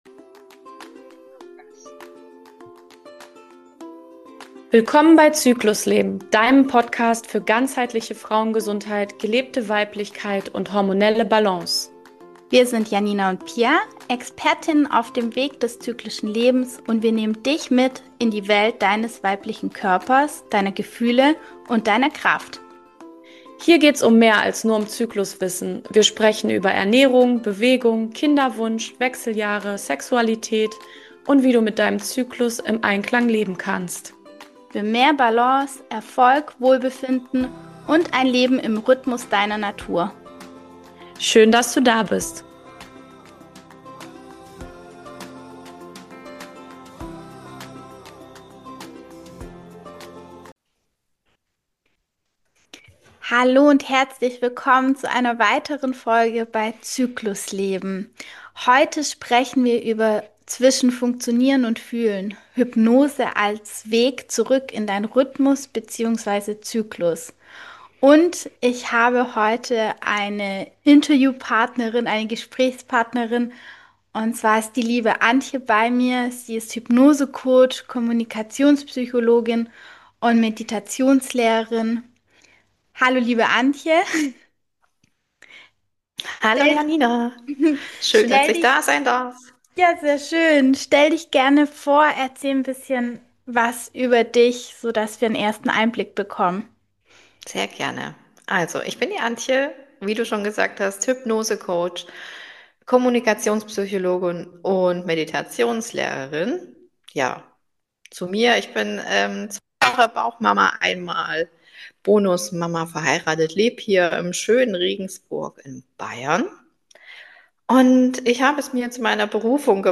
Am Ende wartet eine kleine Anker-Übung auf dich – für mehr Verbindung, innere Sicherheit und ein liebevolleres Spüren deines Körpers.